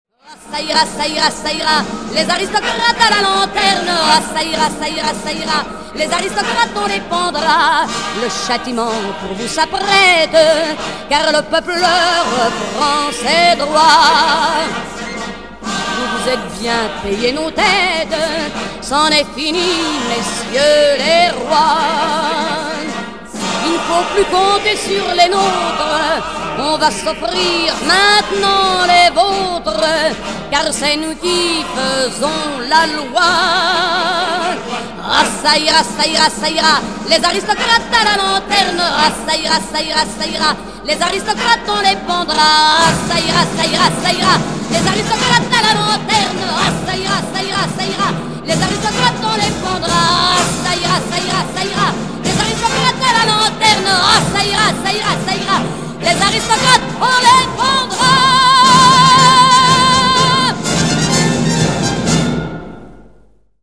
«Радикальный» текст песенки «Ça ira» звучит в исполнении Эдит Пиаф (скачать):